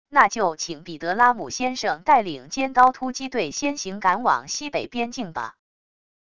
那就请彼得拉姆先生带领尖刀突击队先行赶往西北边境吧wav音频生成系统WAV Audio Player